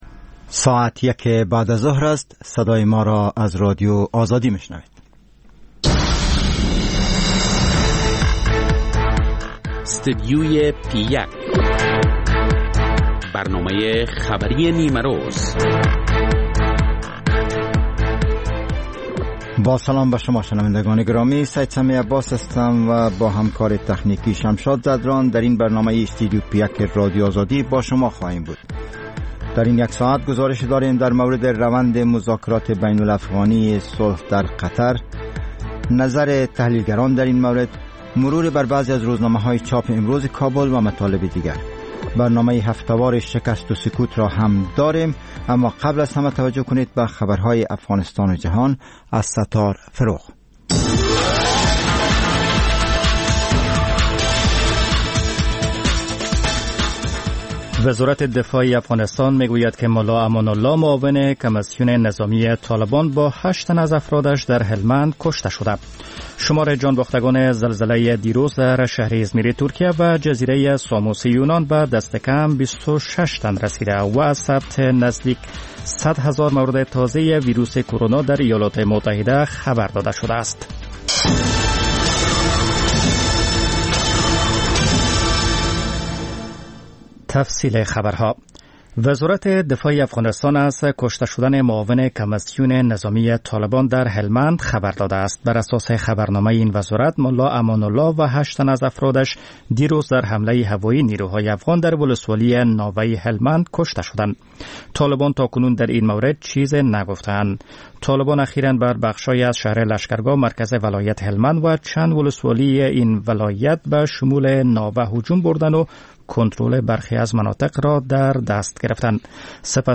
خبر ها و گزارش‌ها